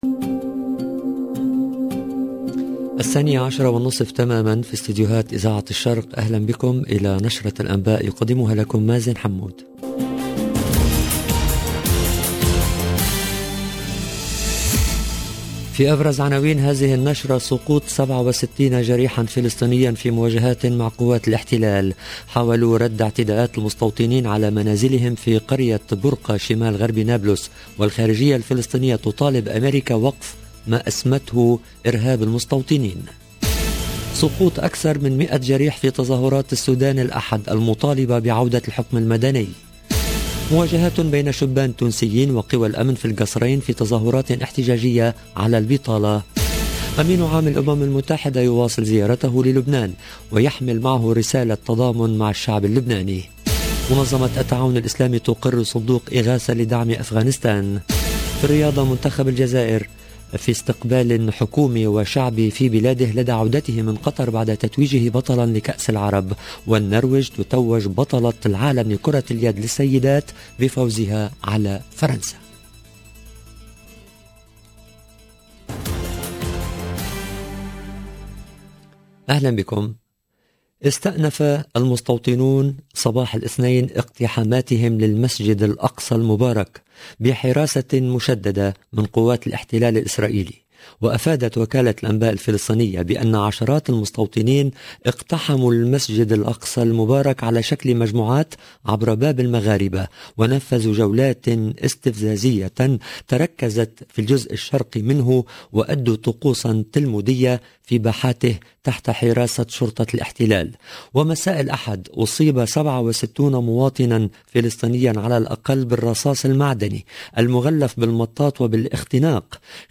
LE JOURNAL EN LANGUE ARABE DE MIDI 30 DU 20/12/21